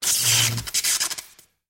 Звуки короткого замыкания
На этой странице собраны звуки короткого замыкания — резкие, трещащие и искрящие аудиоэффекты.
Шумы неполадок в электроприборе